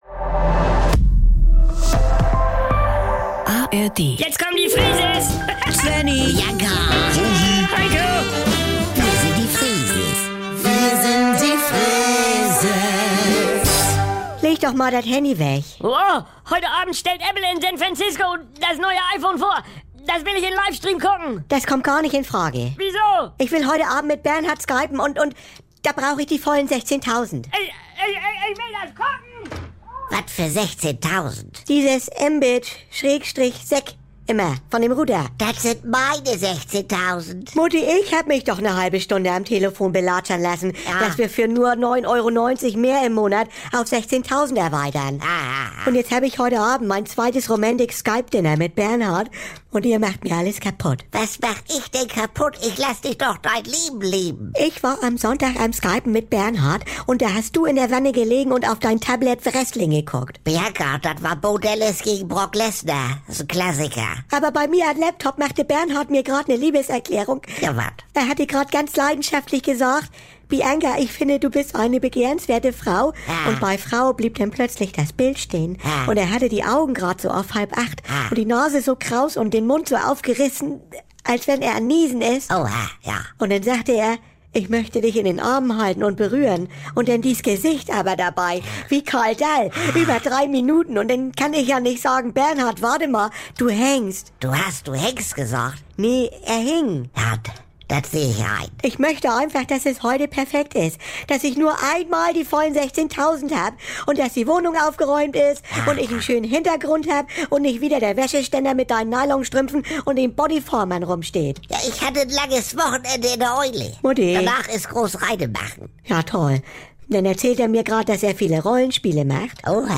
Saubere Komödien Unterhaltung NDR 2 Komödie NDR Freeses Comedy